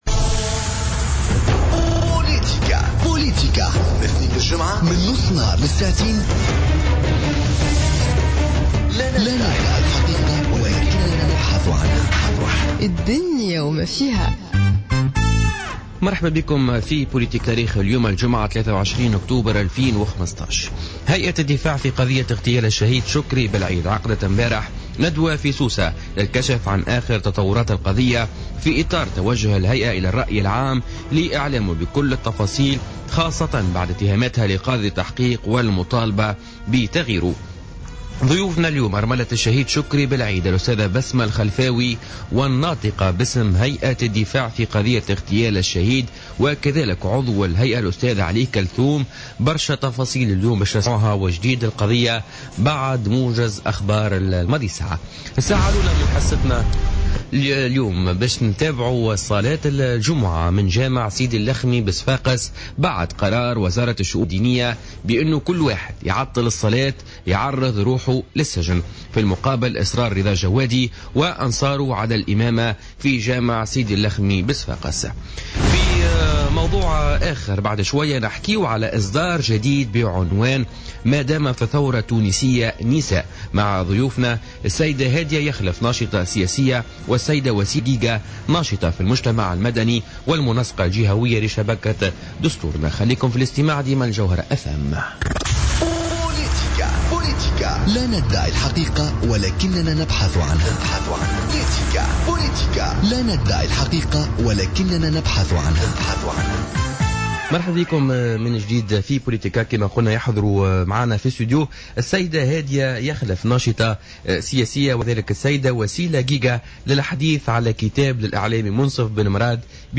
Interview avec Besma Khalfaoui, la veuve du martyr Chokri Belaid